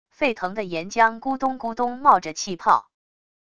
沸腾的岩浆咕咚咕咚冒着气泡wav音频